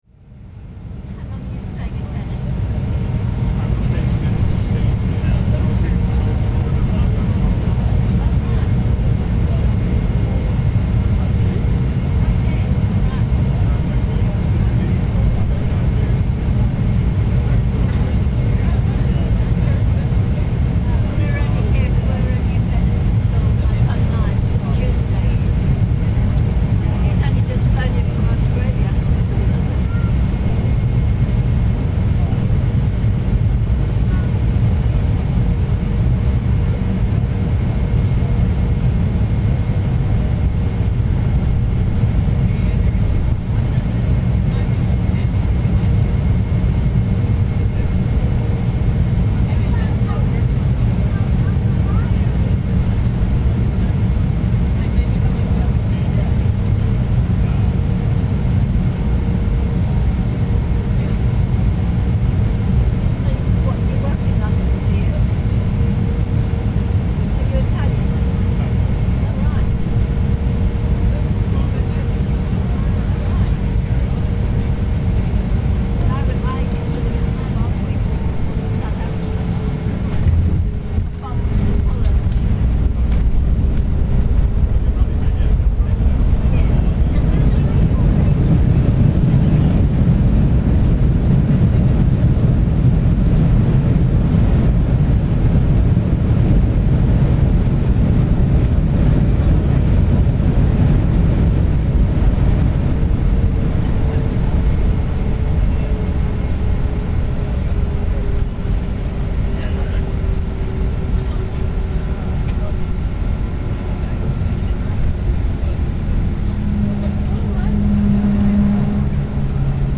A flight from London Gatwick comes in to land at Venice Marco Polo airport - recording taken from on board the plane.